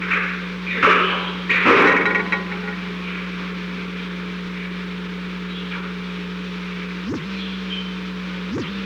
Secret White House Tapes
Location: Oval Office
The President met with an unknown person
[Unintelligible]